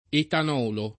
[ etan 0 lo ]